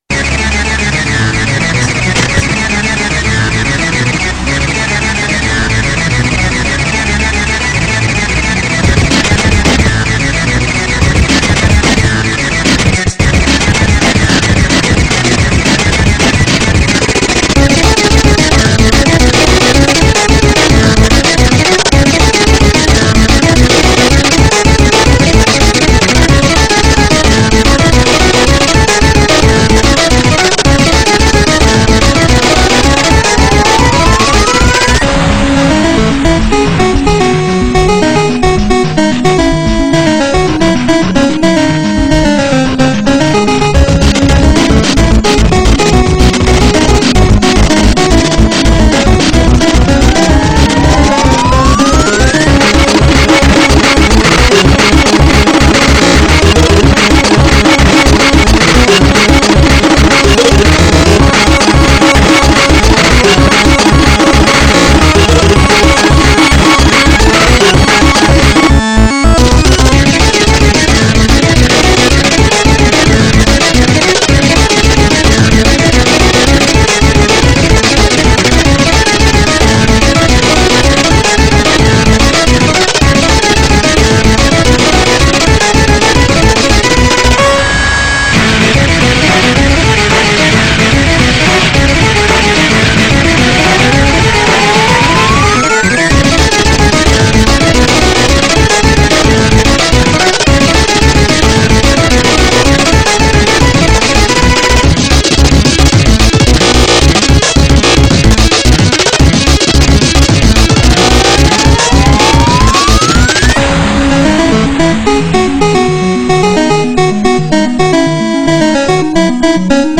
BPM110-220
Audio QualityPerfect (High Quality)